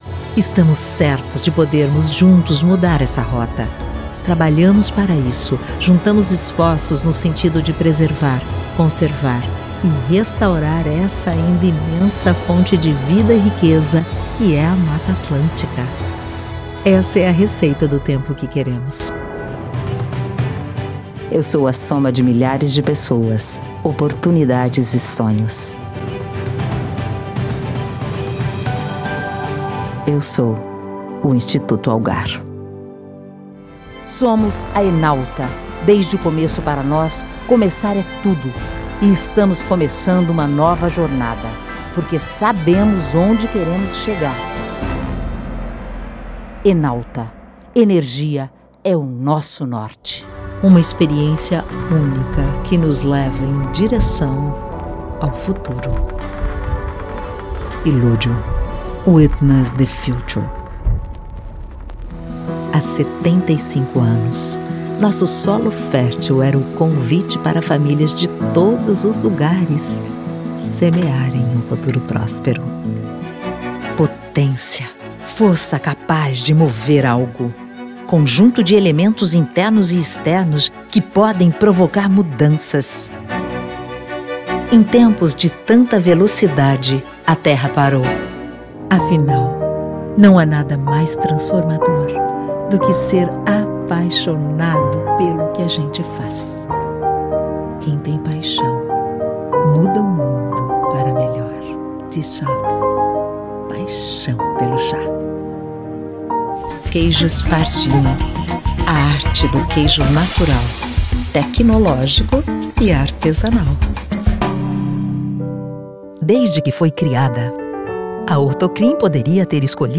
Repertório Institucional / Manifesto
Voz Padrão - Grave 02:36
Owns an at home recording studio.